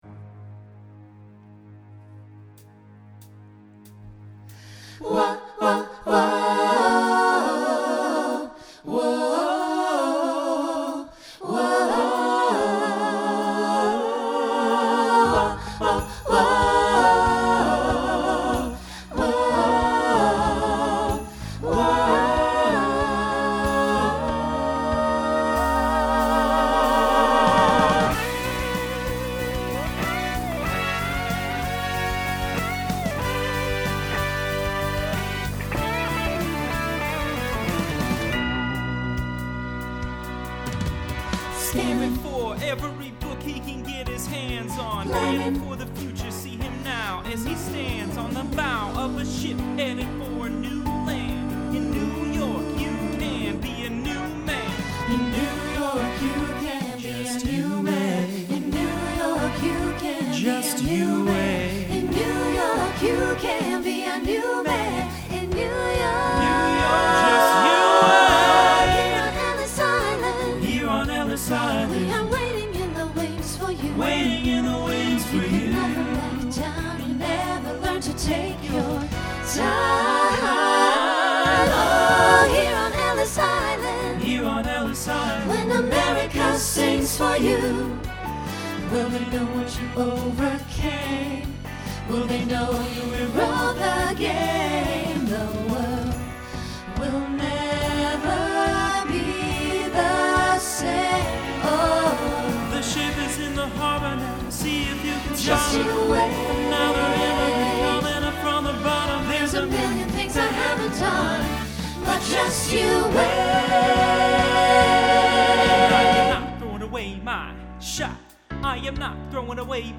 Broadway/Film
Story/Theme Voicing SATB